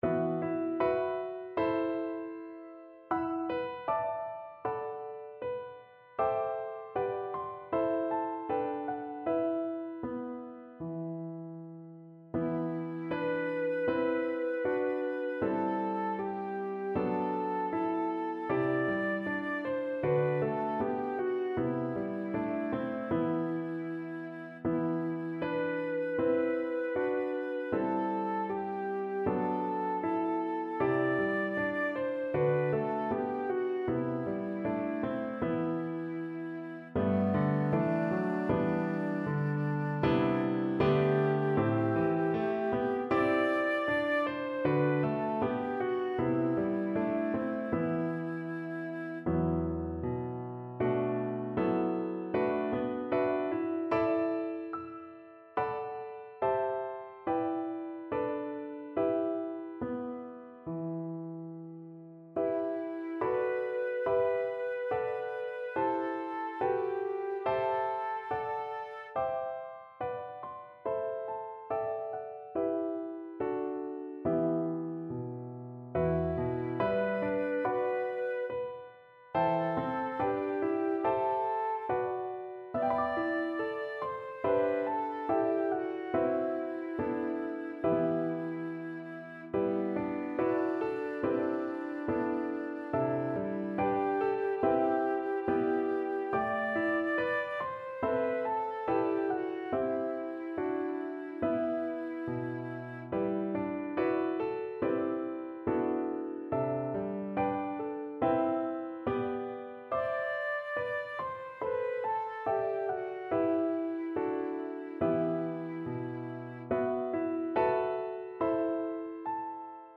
Piano Arrangements
Here is a digital download to my vocal/piano arrangement of All the Pretty Little Horsies.
piano.all-the-pretty-little-horsies.recording-version-1.mp3